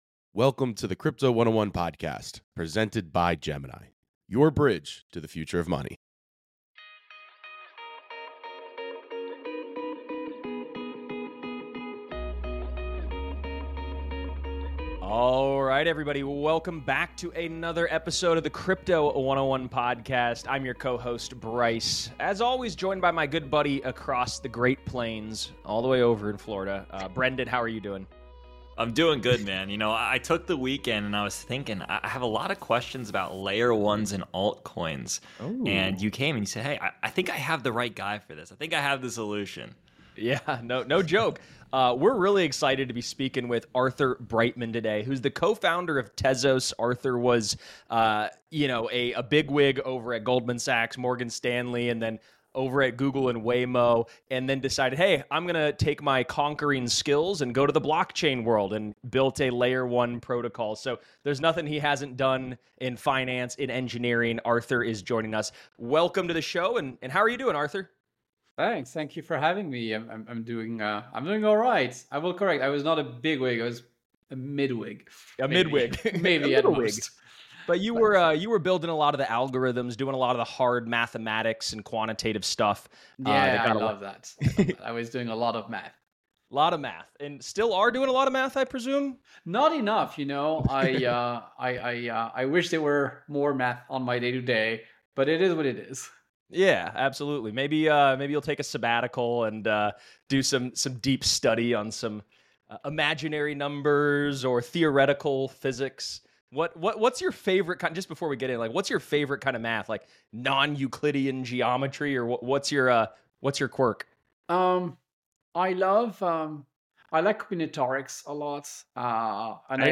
In this explosive Heretics interview